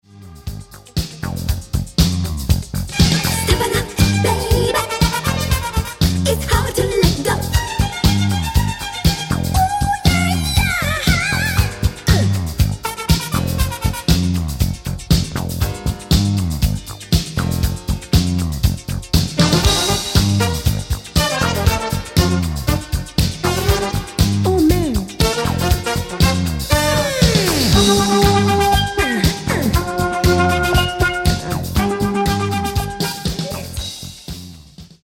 Genere: Disco | Soul | Funky